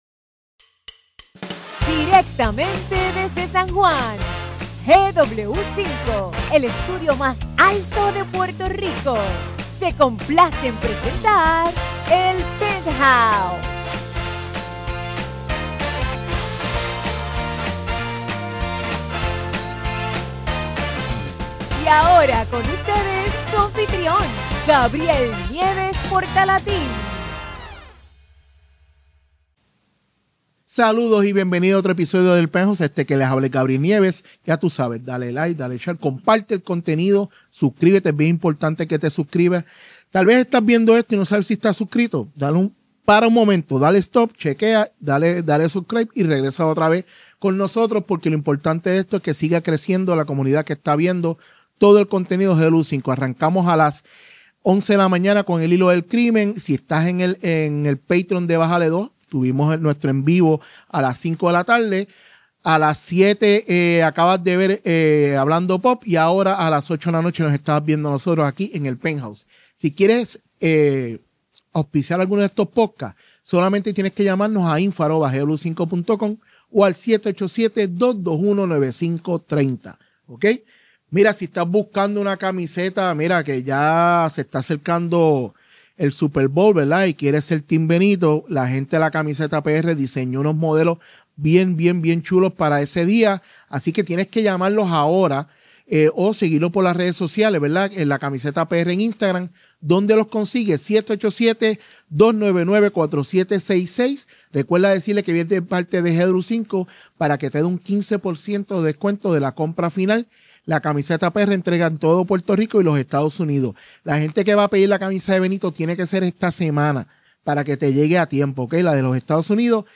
Grabado desde GW-Cinco Studio